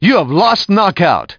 1 channel
ko-youhavelostko.mp3